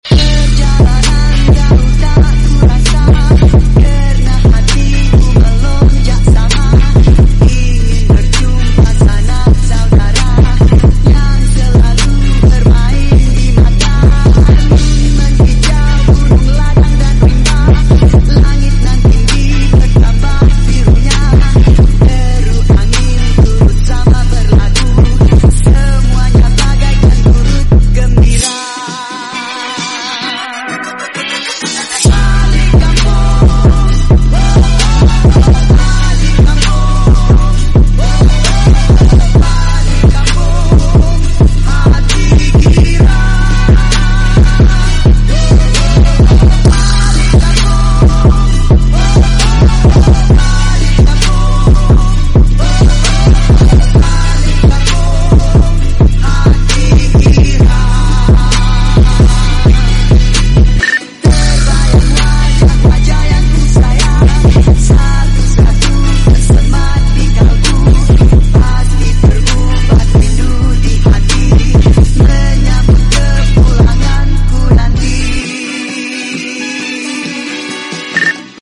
BASS BOOSTED